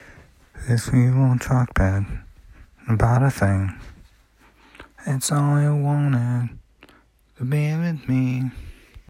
START WITH SINGLE STRUM